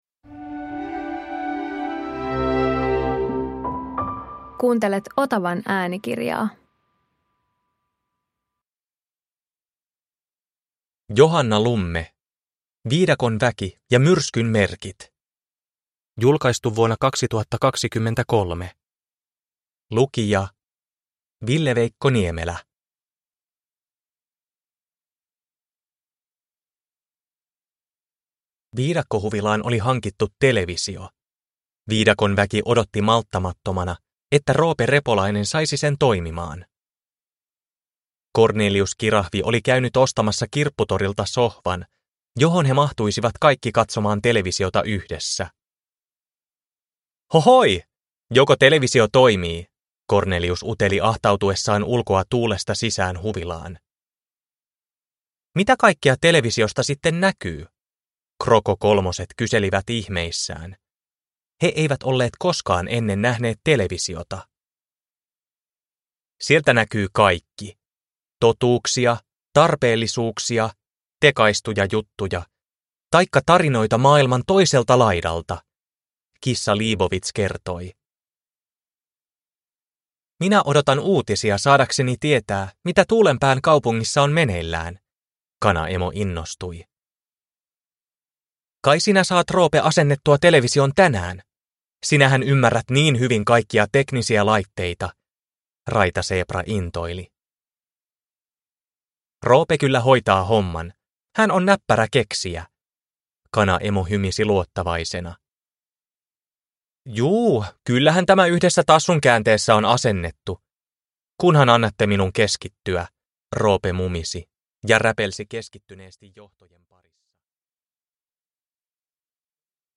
Viidakon väki ja myrskyn merkit – Ljudbok – Laddas ner